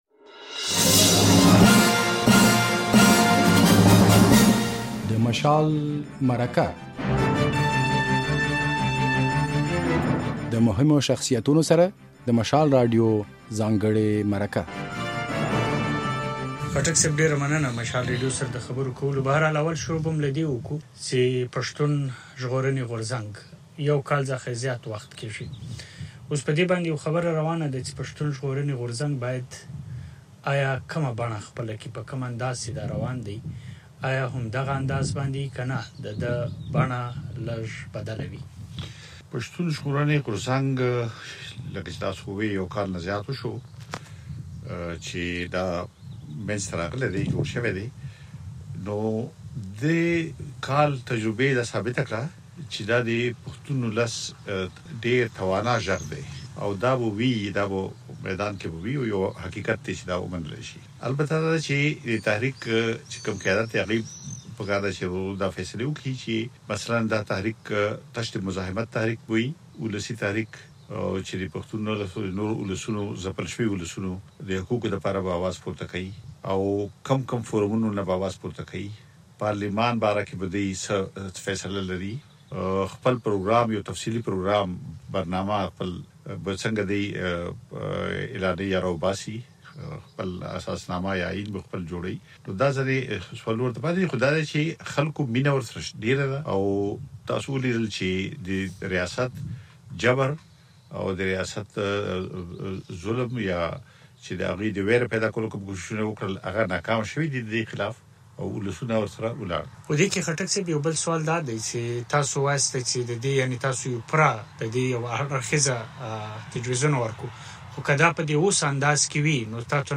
له افراسیاب خټک سره د مشال مرکه
د "مشال مرکې" د دې اوونۍ په خپرونه کې مو له سیاستوال، فعال او لیکوال افراسیاب خټک سره خبرې کړې دي.